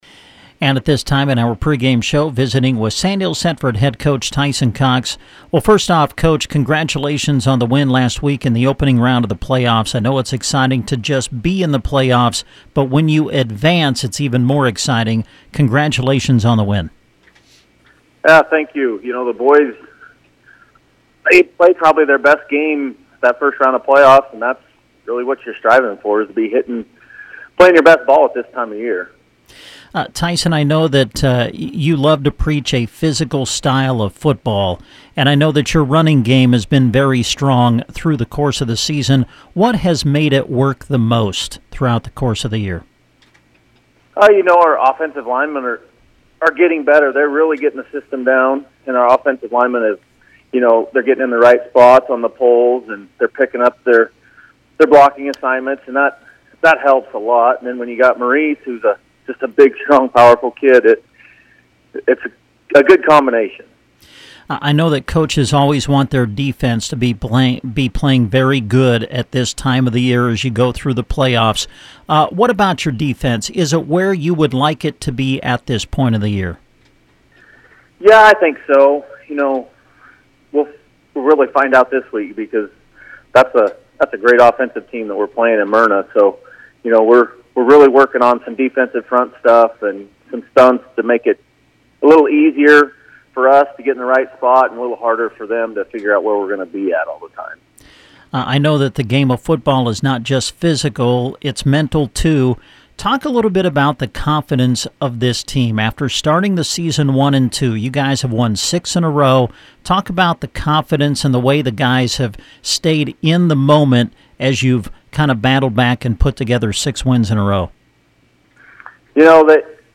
INTERVIEW WITH COACH